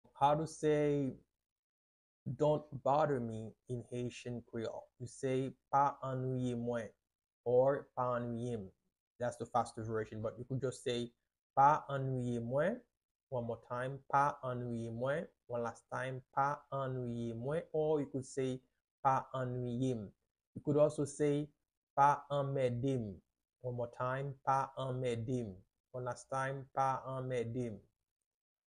How to say “Don't Bother Me” in Haitian Creole - “Pa Anwiye mwen” pronunciation by a native Haitian Teacher
“Pa Anwiye mwen” Pronunciation in Haitian Creole by a native Haitian can be heard in the audio here or in the video below:
How-to-say-Dont-Bother-Me-in-Haitian-Creole-Pa-Anwiye-mwen-pronunciation-by-a-native-Haitian-Teacher.mp3